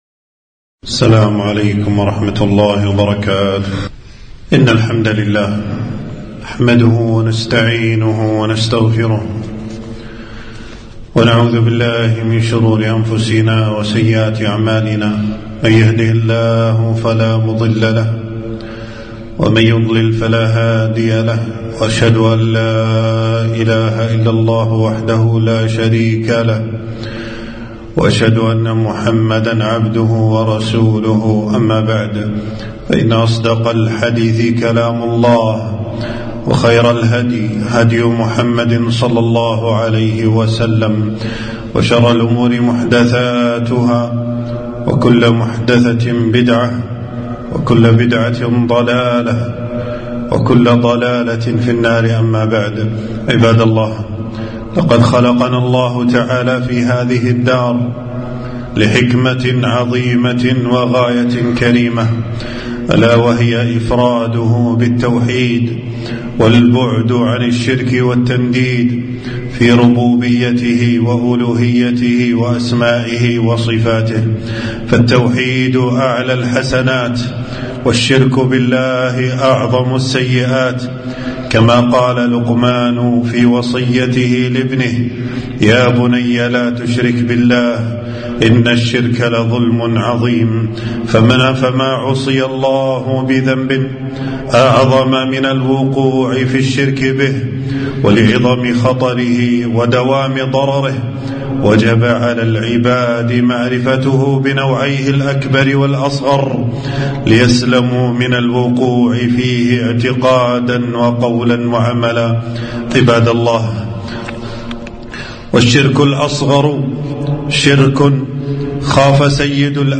خطبة - خطورة الشِّرْك الْأَصْغَر وبعض أنواعه